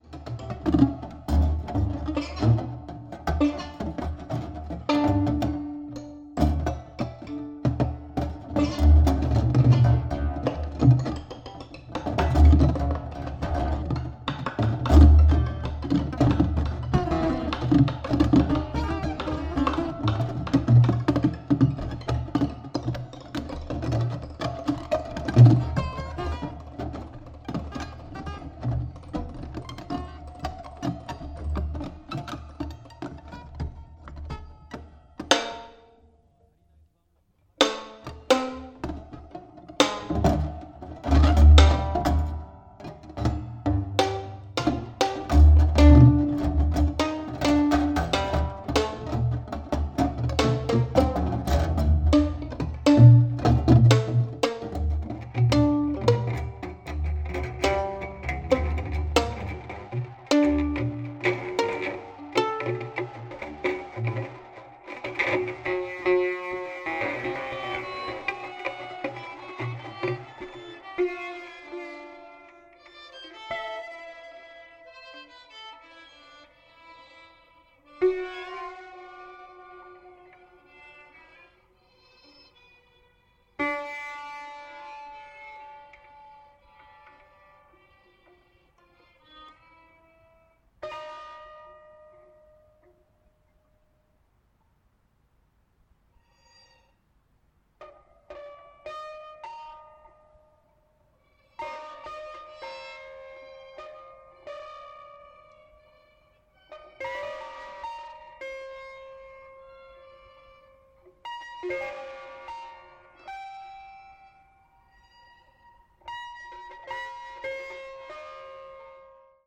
piano, clavichord, zither, electronics